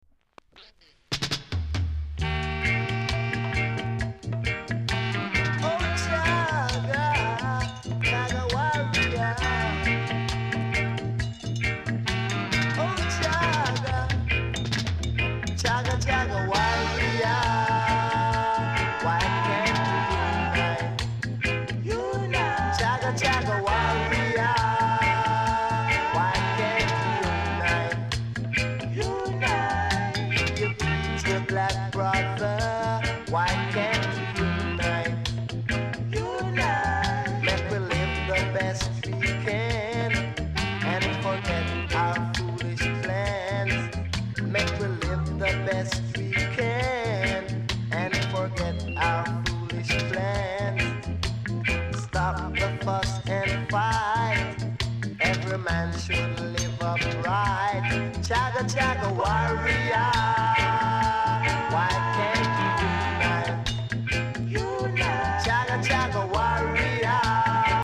※多少小さなノイズはありますが概ね良好です。
コメント NICE ROOTS!!